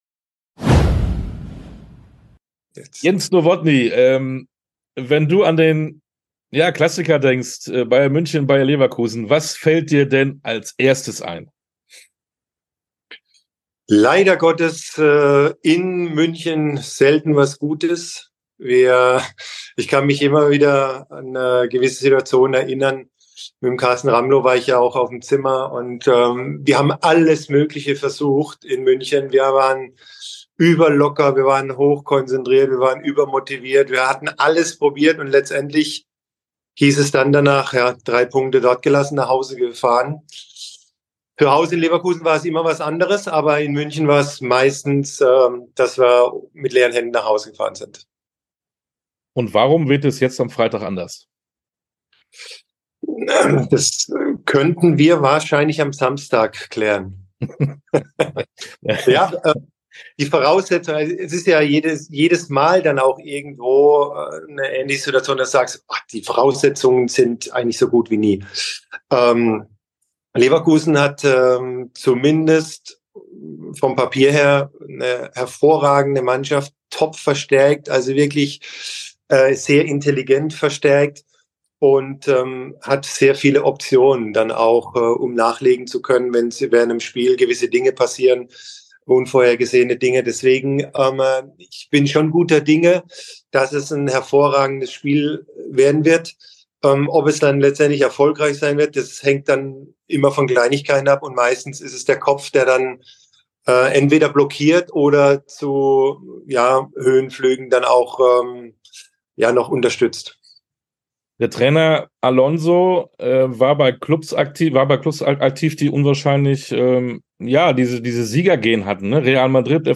Sportstunde - Interview mit Jens Nowotny ~ Sportstunde - Interviews in voller Länge Podcast
Interview_komplett_-_Jens_Nowotny.mp3